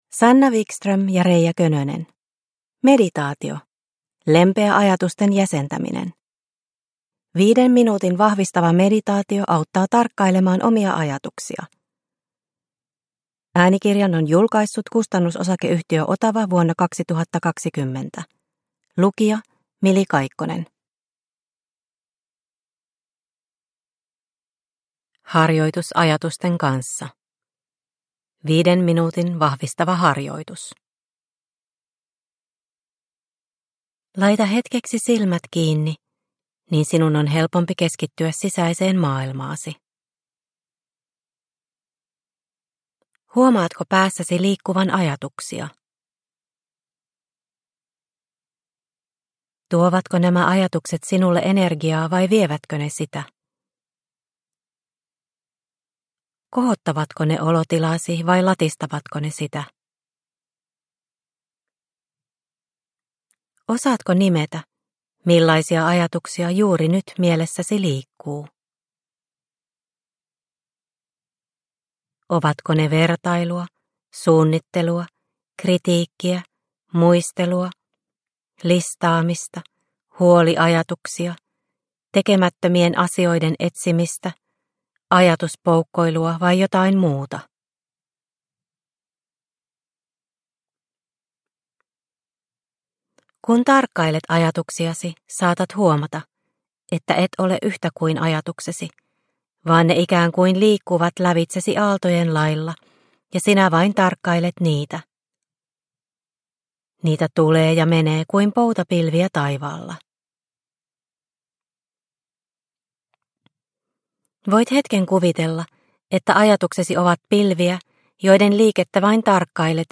Meditaatio - Lempeä ajatusten jäsentäminen – Ljudbok – Laddas ner